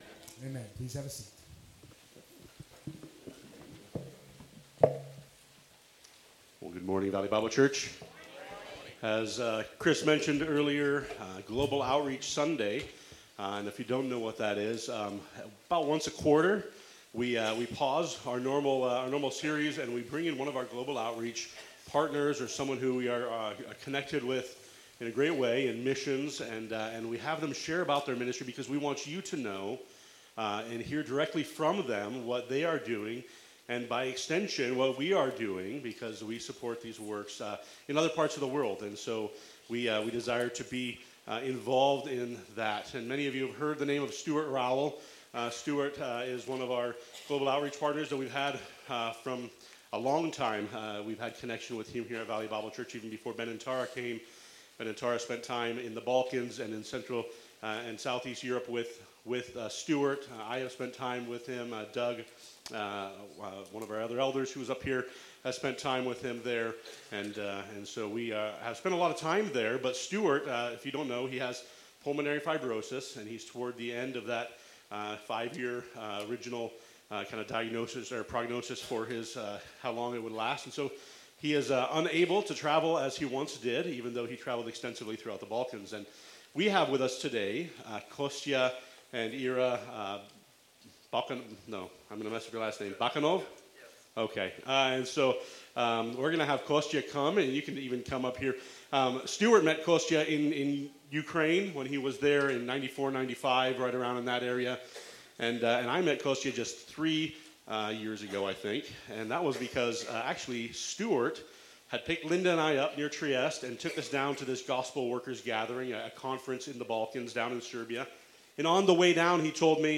March 22’s Sunday service livestream, bulletin/sermon notes/Life Group questions, the online Connection Card, and playlists of Sunday’s music (Spotify and YouTube).